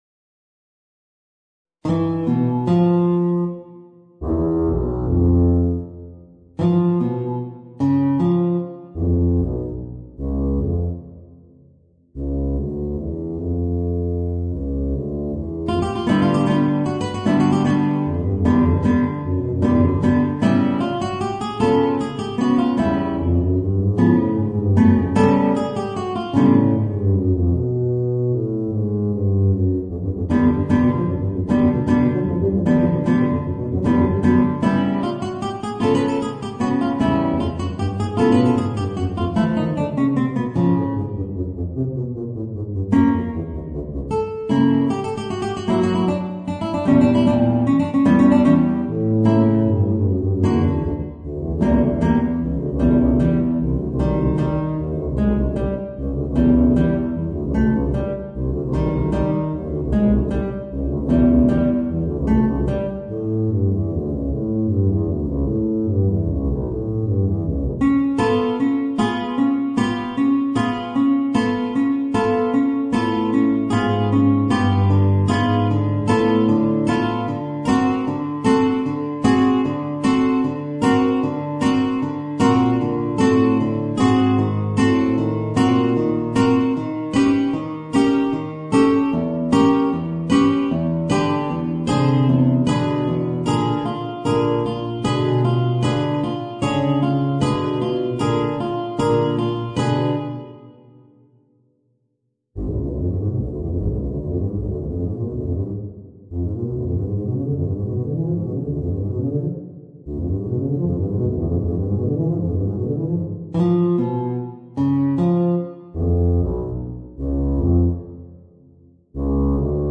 Voicing: Guitar and Tuba